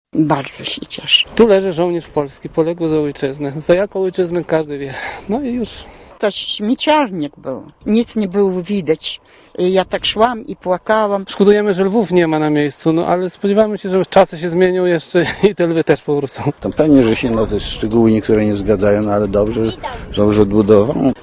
Mówią Polacy ze Lwowa
cmentarz-polacy-lwow.mp3